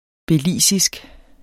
belizisk adjektiv Bøjning -, -e Udtale [ beˈliˀsisg ] Betydninger fra Belize; vedr.